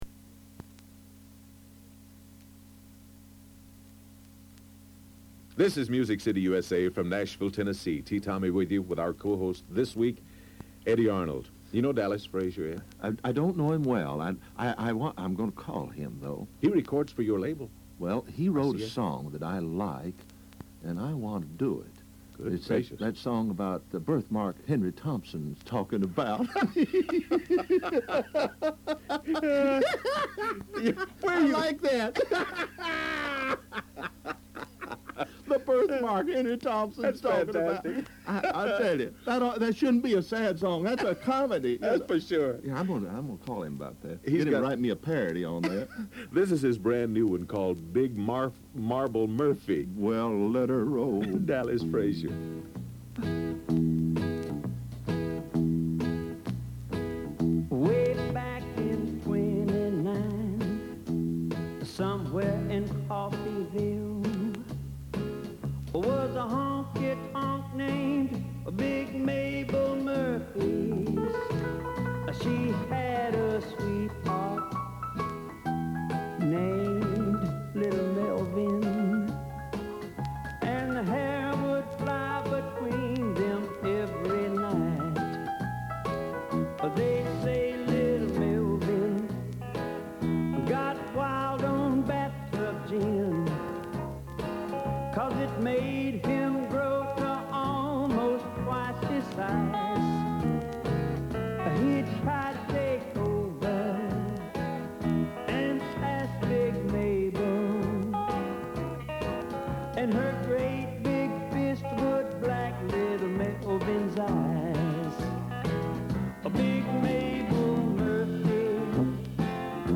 Co-Host Eddy Arnold